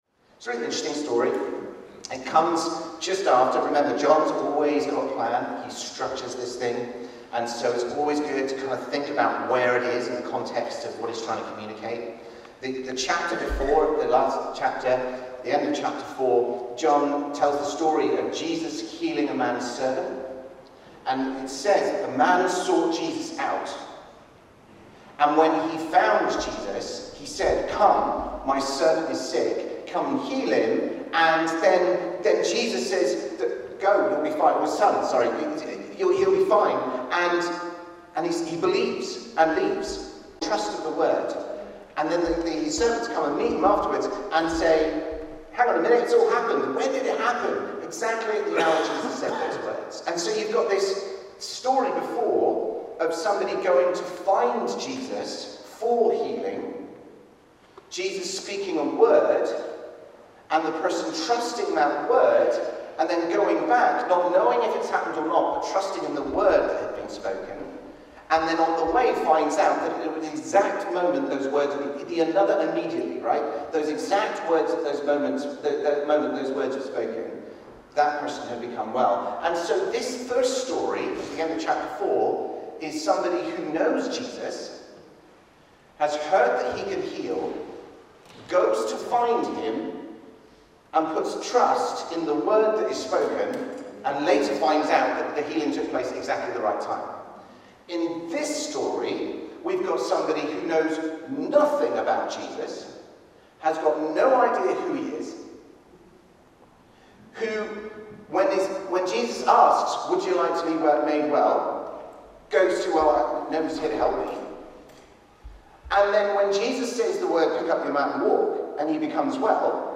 Sermon - John 5: 1-15
View the live recording from our Sunday service.